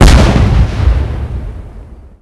Index of /cstrike_backup/sound/knifes/hammer
hit_wall.wav